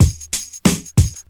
93 Bpm Drum Loop Sample E Key.wav
Free drum beat - kick tuned to the E note. Loudest frequency: 1893Hz
.WAV .MP3 .OGG 0:00 / 0:01 Type Wav Duration 0:01 Size 224,38 KB Samplerate 44100 Hz Bitdepth 16 Channels Stereo Free drum beat - kick tuned to the E note.
93-bpm-drum-loop-sample-e-key-256.ogg